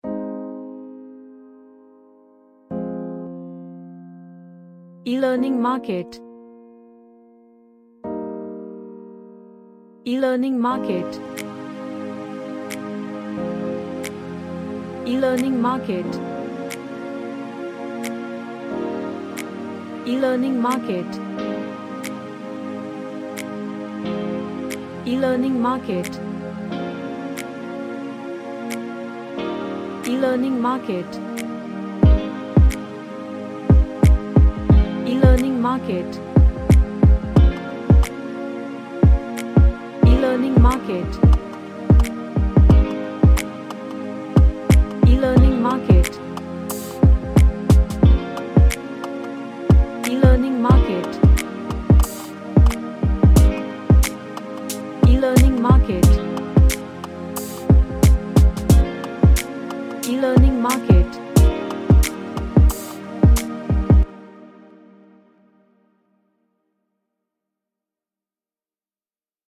A nice ambient track with groove
Happy